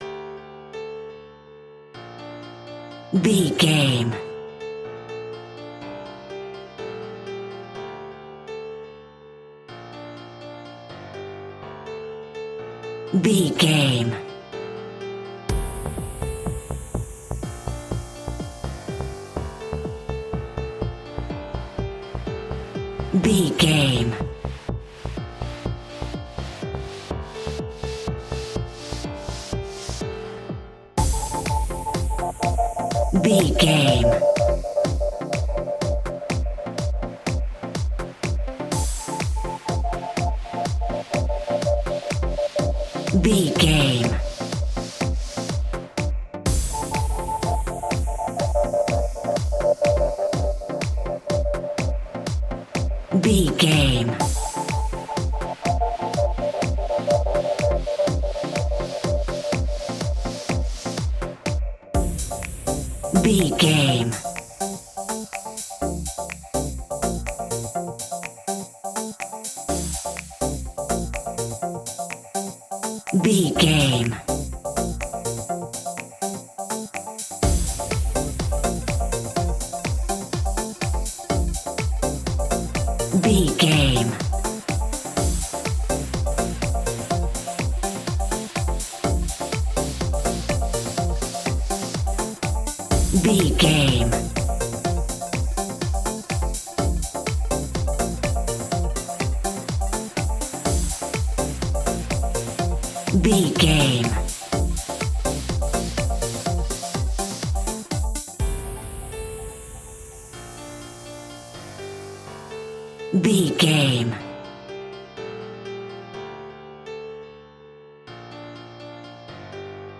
royalty free music
Fast paced
Aeolian/Minor
aggressive
dark
driving
energetic
piano
drum machine
synthesiser
breakbeat
synth leads
synth bass